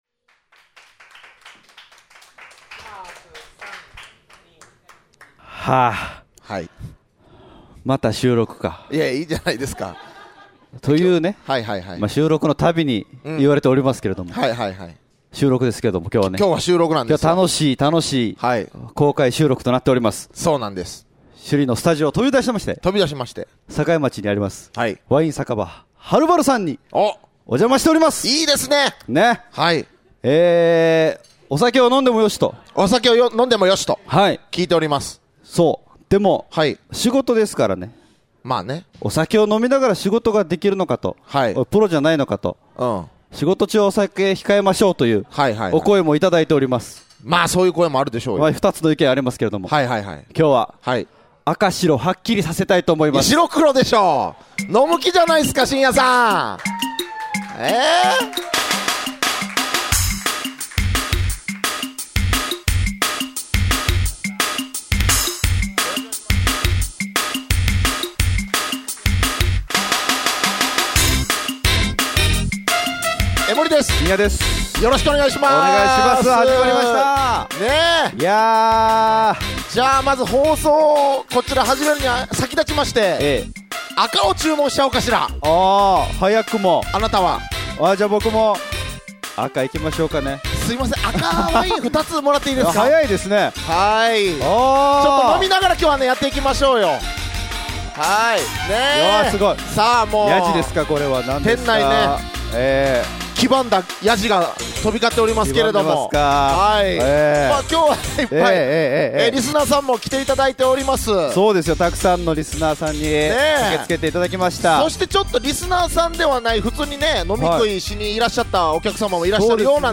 160122リップサービスのオリジンアワー公開収録 in ハル晴ル